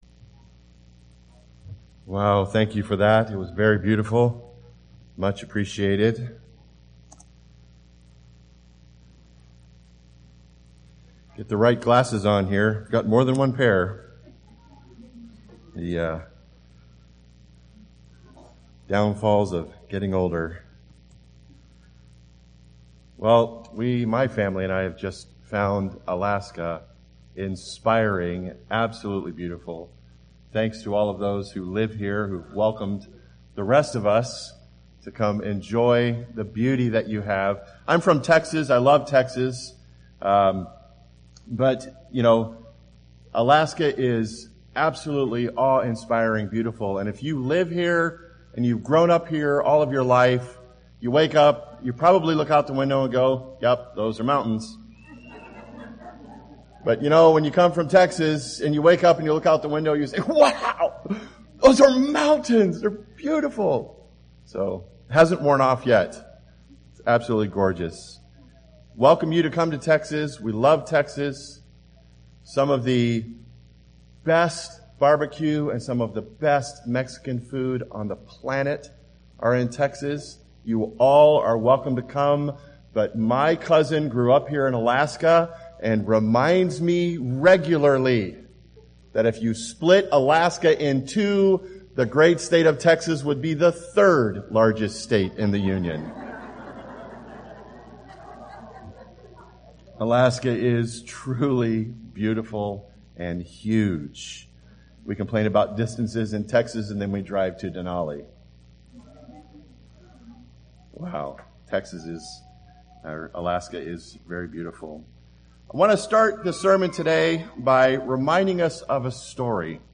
This sermon was given at the Anchorage, Alaska 2018 Feast site.